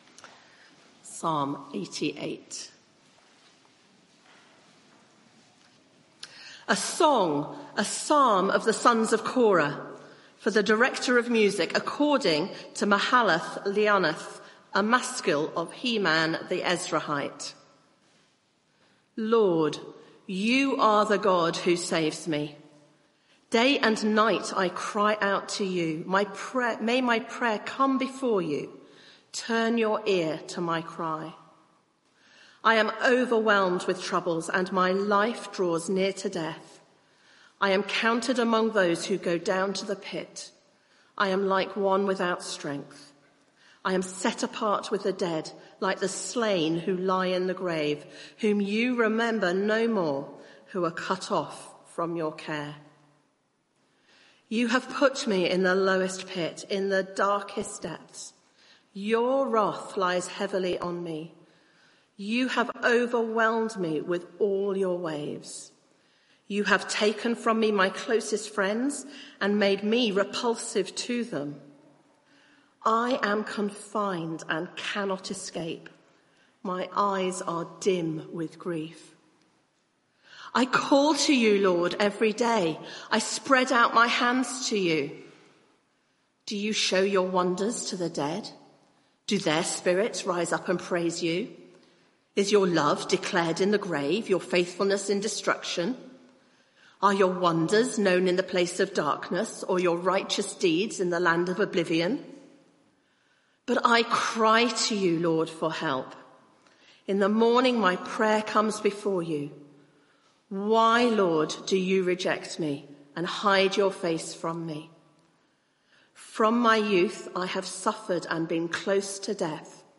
Media for 9:15am Service on Sun 10th Aug 2025 09:15 Speaker
Psalm 88 Series: Jesus' Prayer Book Theme: Psalm 88 Sermon (audio)